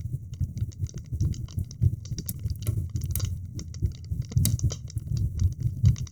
torch_loop.wav